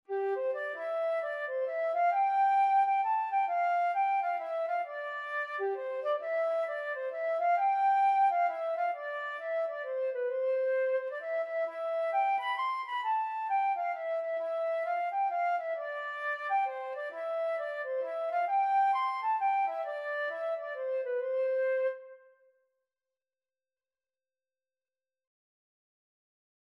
C major (Sounding Pitch) (View more C major Music for Flute )
3/4 (View more 3/4 Music)
G5-C7
Flute  (View more Easy Flute Music)
Traditional (View more Traditional Flute Music)